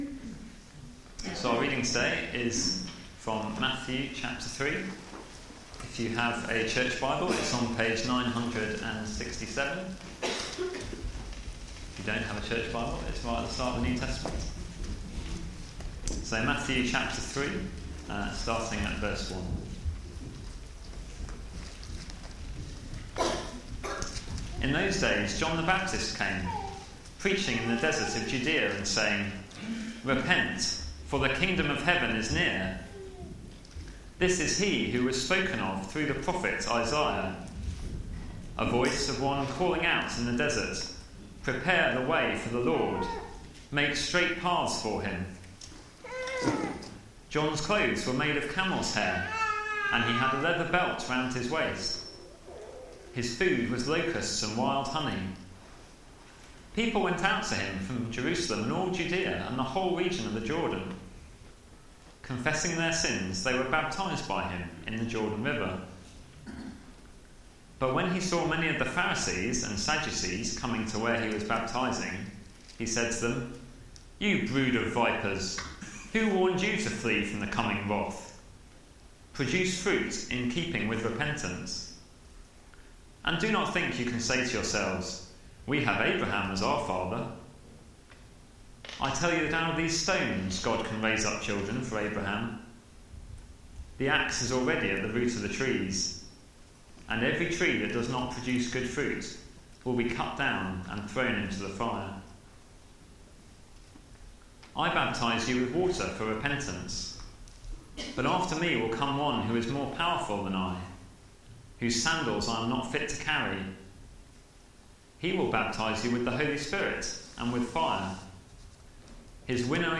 Media for Sunday Service on Sun 15th Dec 2013 10:00